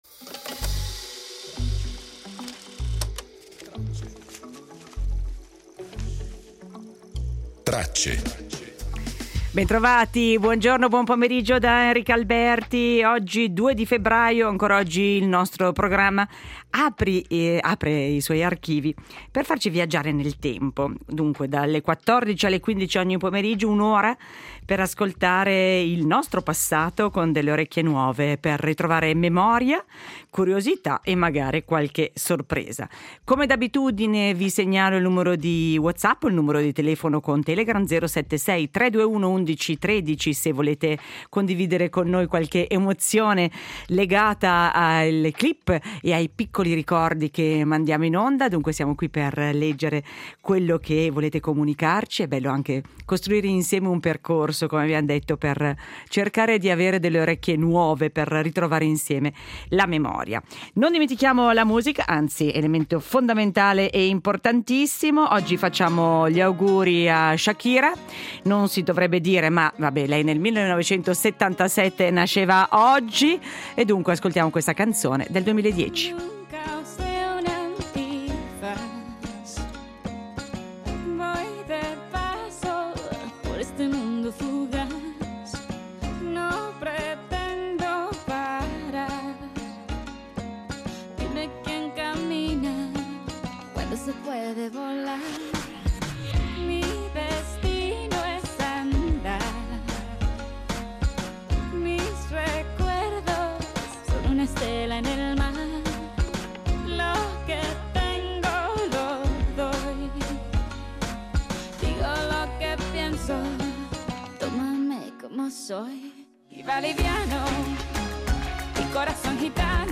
Celebriamo Aldo Palazzeschi nel giorno della sua nascita con un’intervista realizzata nel 1966 nella sua casa romana.